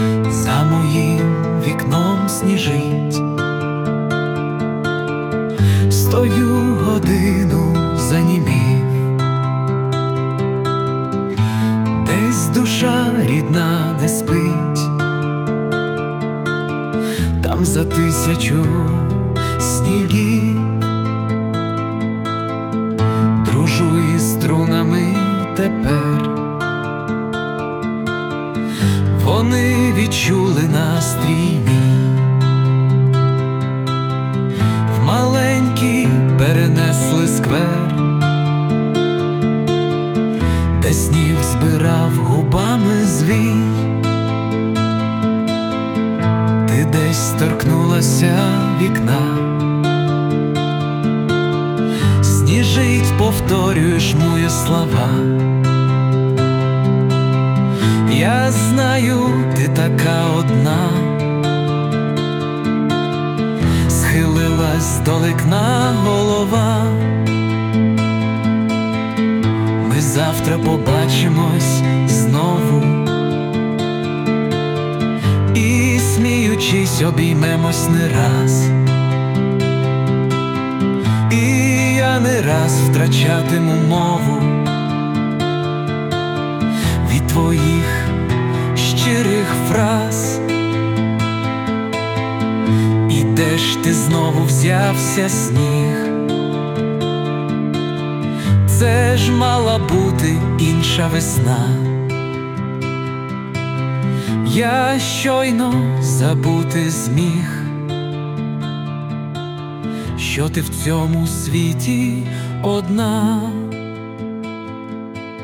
Власне виконання
Перепади звуку усунуто з допомогою SUNO
СТИЛЬОВІ ЖАНРИ: Ліричний
Чарівна мелодія кохання!
Що то значить живе виконання...